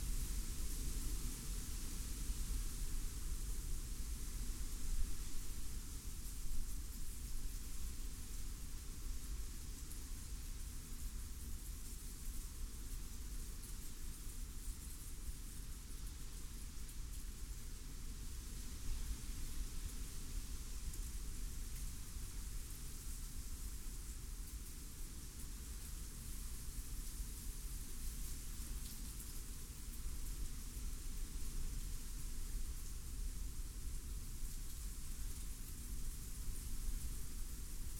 Wind.wav